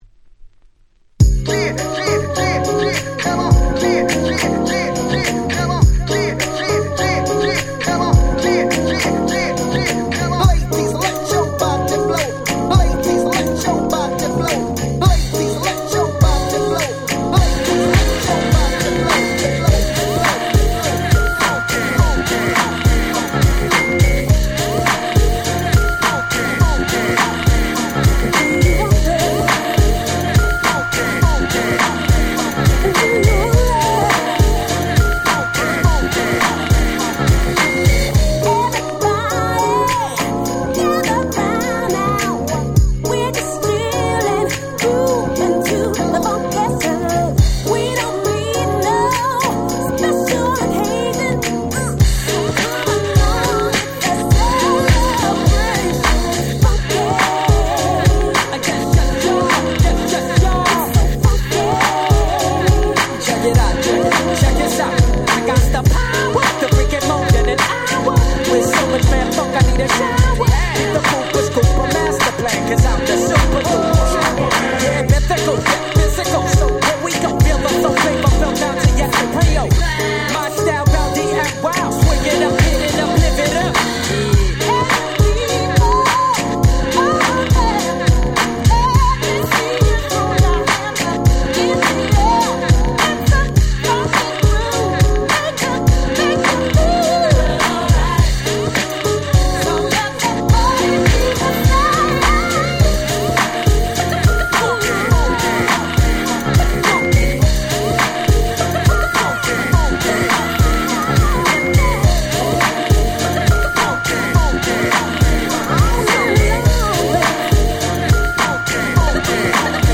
90's R&B デリシャスミックス 勝手にリミックス ミックス物